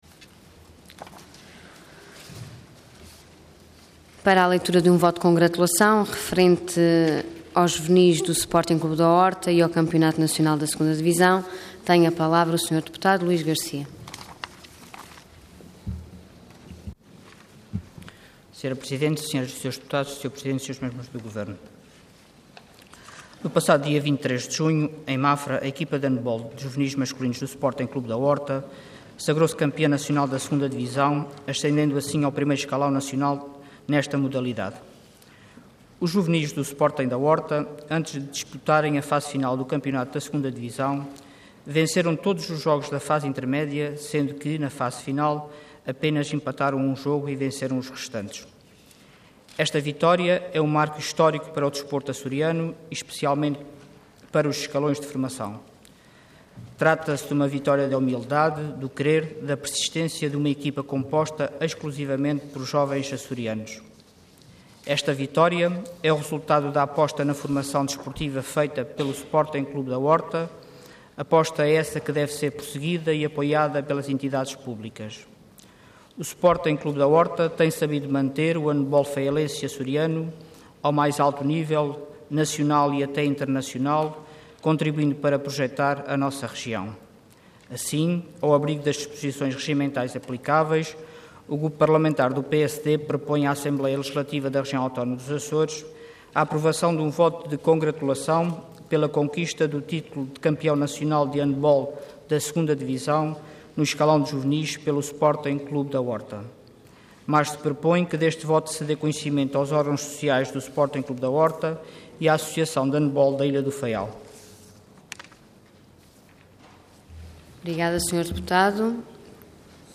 Intervenção Voto de Congratulação Orador Luís Garcia Cargo Deputado Entidade PSD